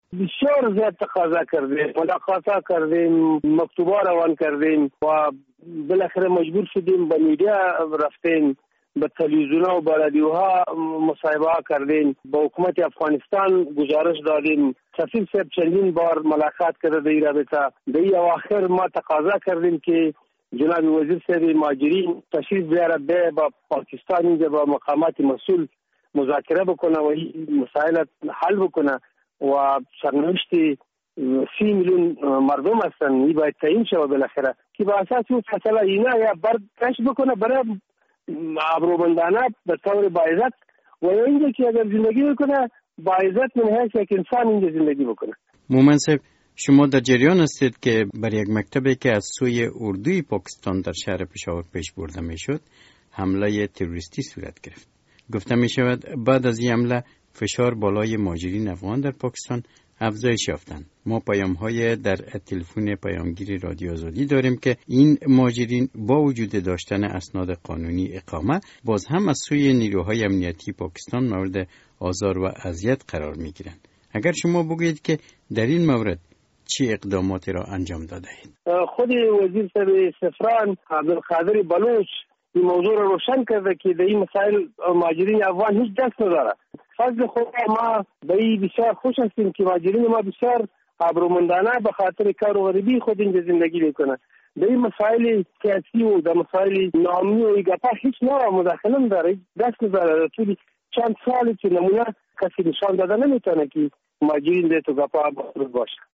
مصاحبه در مورد مشکلات مهاجرین افغان در پاکستان